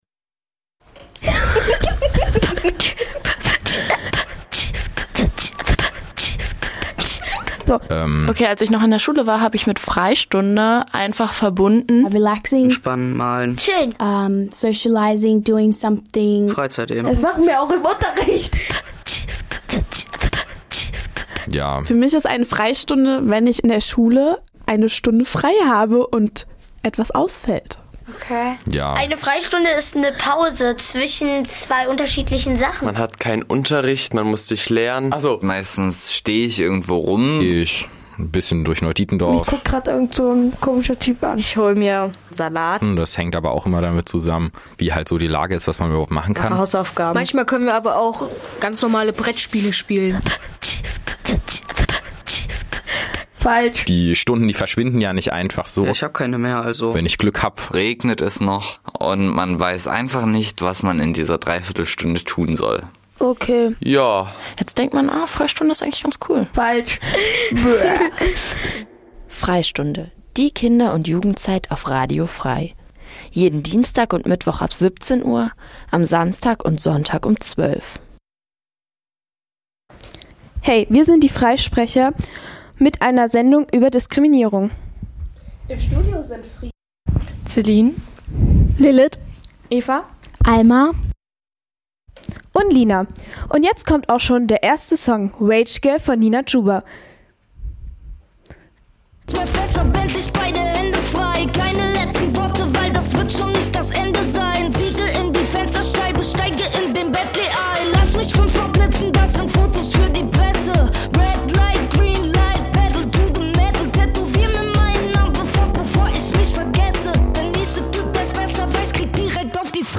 Programm von Kindern und Jugendlichen für Kinder und Jugendliche Dein Browser kann kein HTML5-Audio.